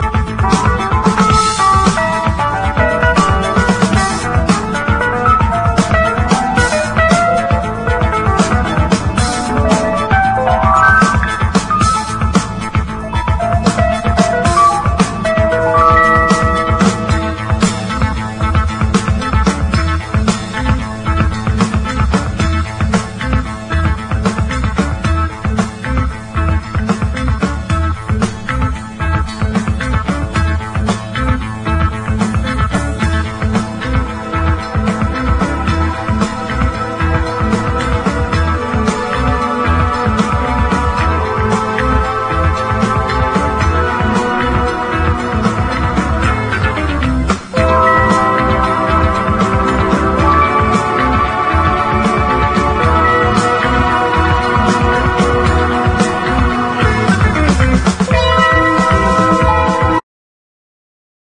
子供コーラス入りのサイケデリック・ポップ/ソフトロック・ナンバーを収録！